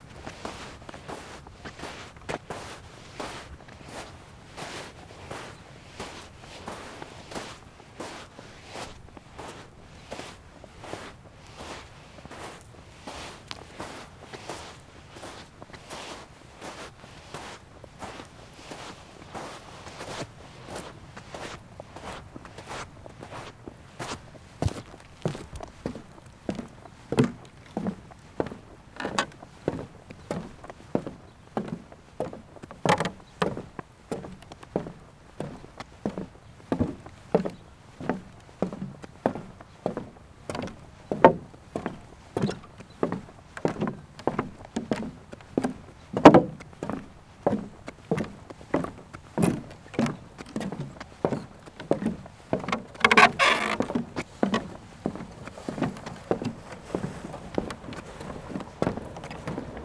footsteps-from-sand-to-walkway.wav
Added and sorted Footstep Samples